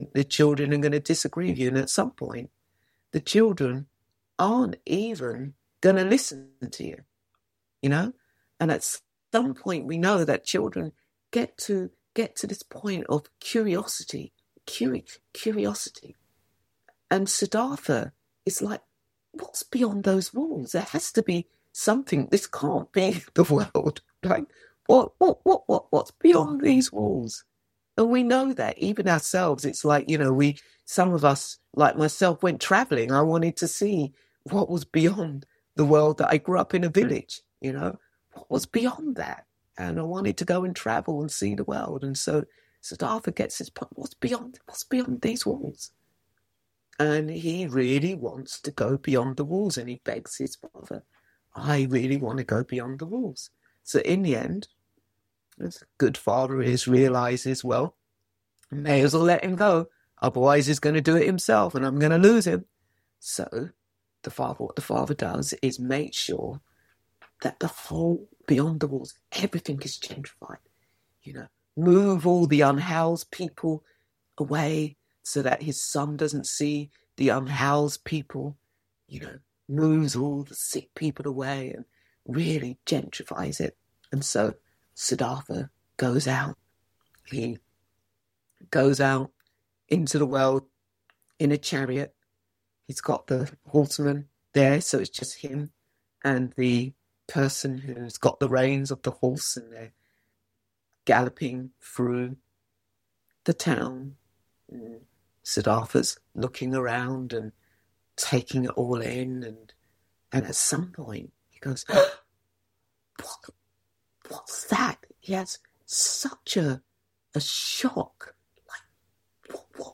(Please note that unfortunately the guided meditation and start of the dharma talk is missing from the video recording and audio file.)